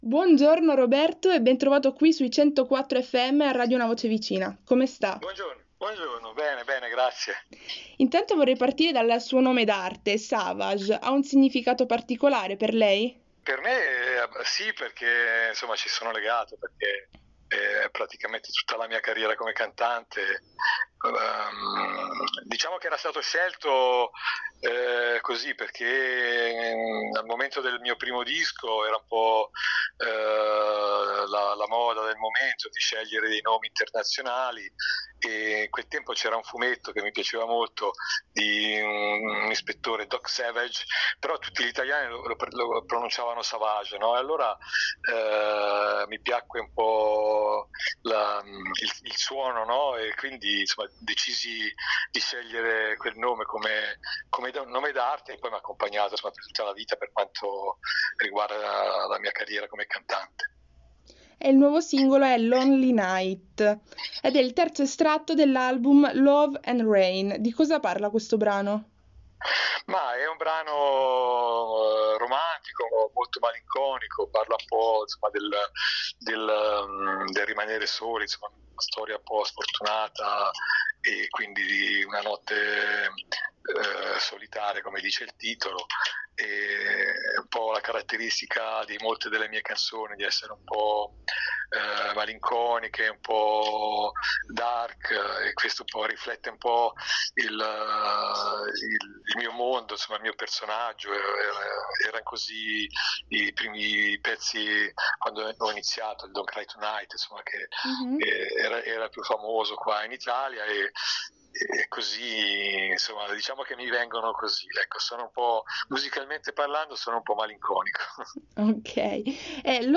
Edit-intervista-Savage.mp3